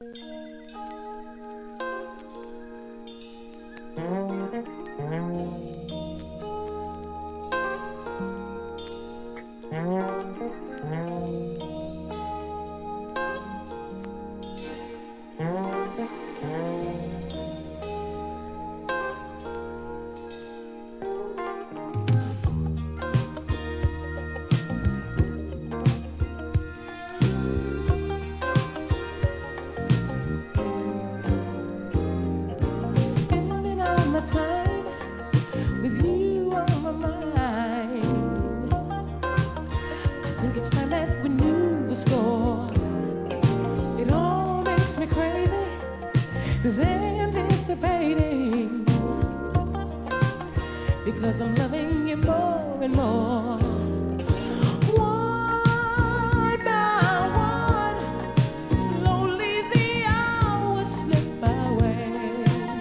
¥ 880 税込 関連カテゴリ SOUL/FUNK/etc...
ゴスペルをバックグラウンドとした姉弟グループ